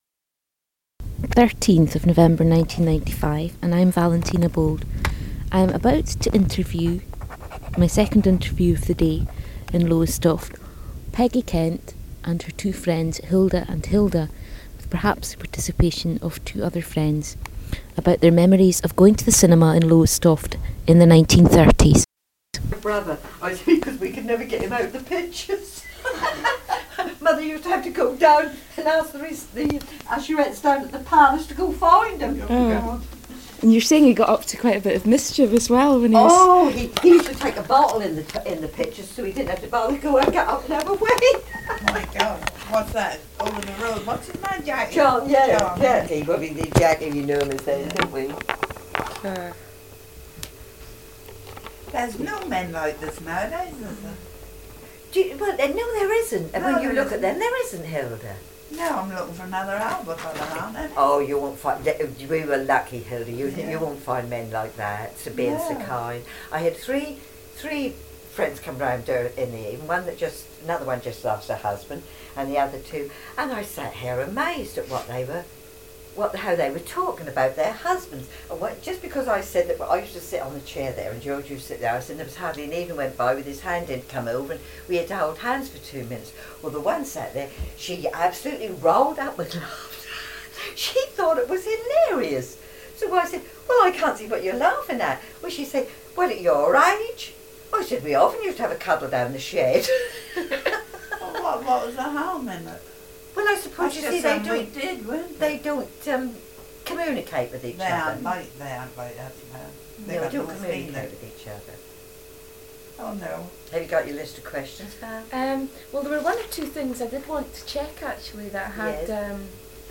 Sound Quality: Fair;